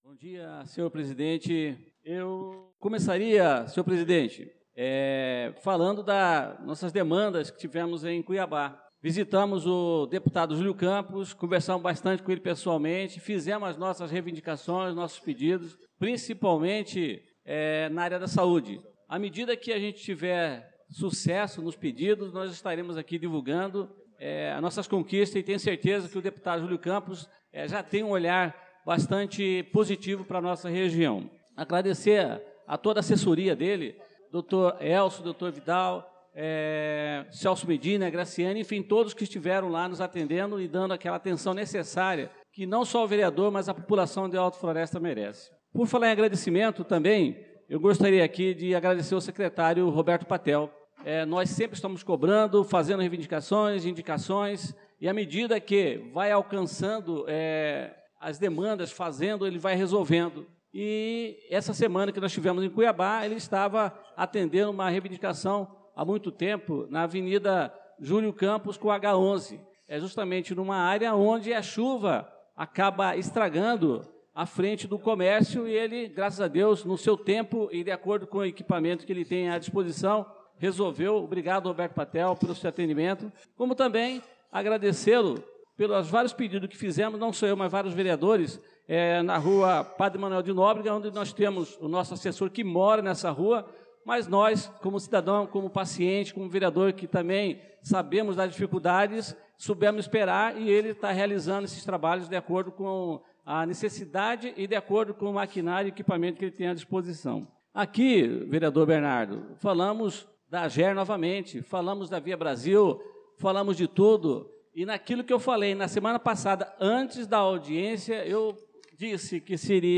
Pronunciamento do vereador Adelson Servidor na Sessão Ordinária do dia 25/03/2025